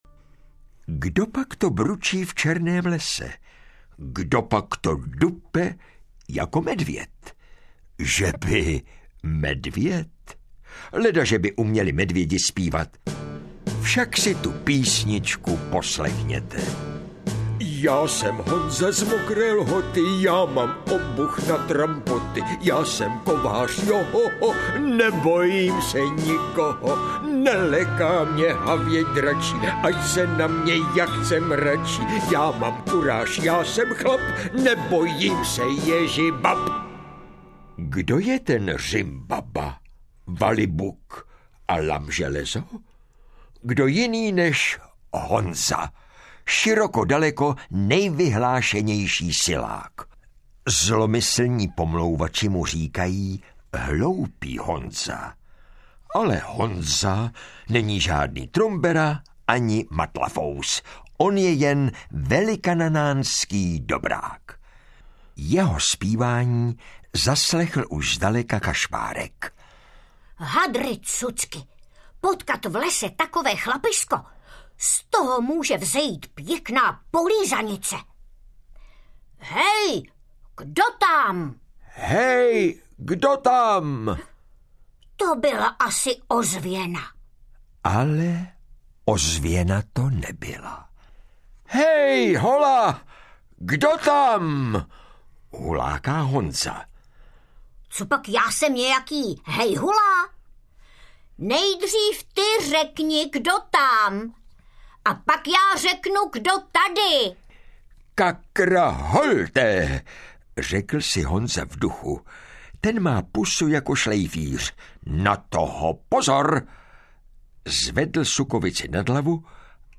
Audiobook
Read: Jan Přeučil